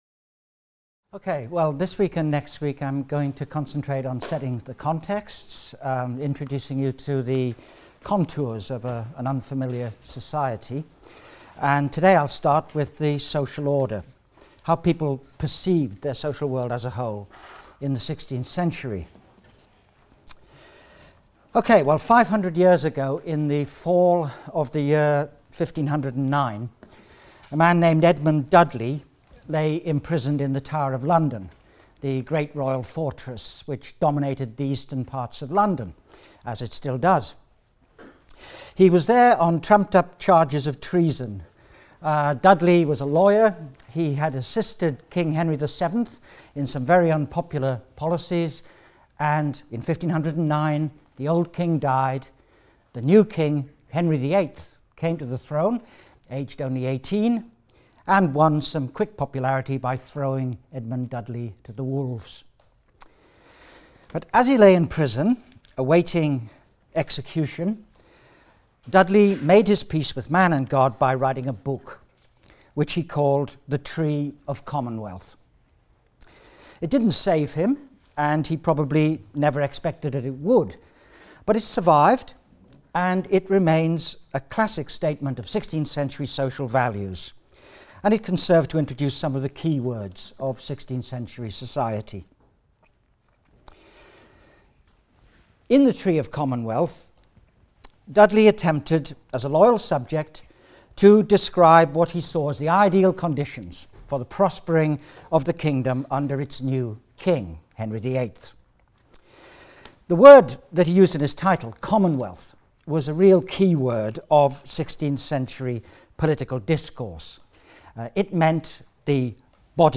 HIST 251 - Lecture 2 - “The Tree of Commonwealth”: The Social Order in the Sixteenth Century | Open Yale Courses